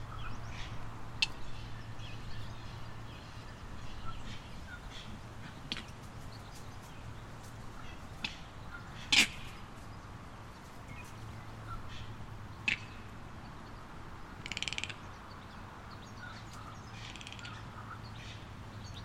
土生土长的母鸡
描述：男性当地母鸡，对入侵者接近的小鸡的警报电话。 2015年10月4日上午11点，塔斯马尼亚州利斯，在Marantz PMD611上录制了Rode NT55
Tag: 本机母鸡 鸟呼叫 塔斯马尼亚